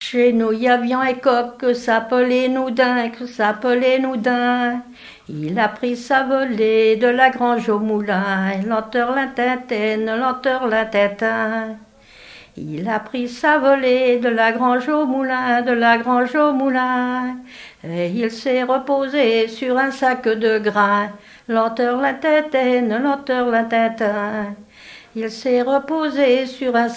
Genre laisse
Enquête Mission Ile-d'Yeu
Pièce musicale inédite